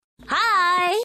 Crawling Soundeffects Sound Effect - Sound Buttons Universe